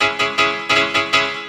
hous-tec / 160bpm / piano